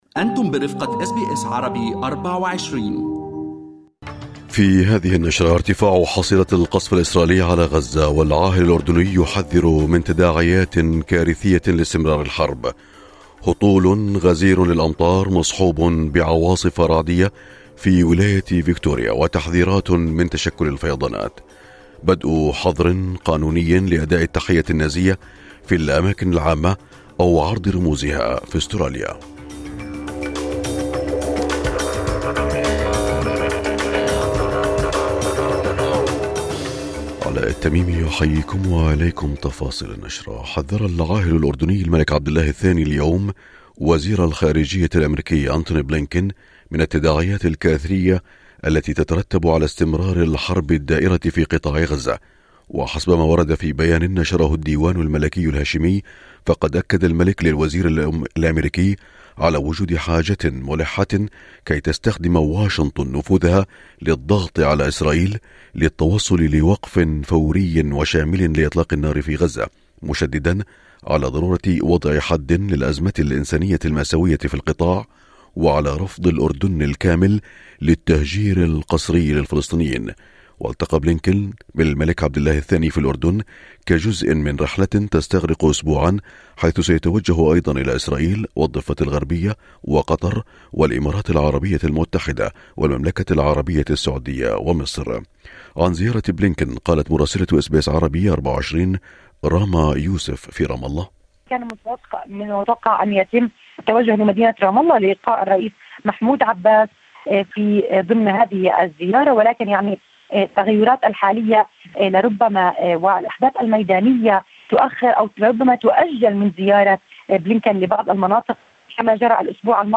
نشرة أخبار الصباح 8/1/2024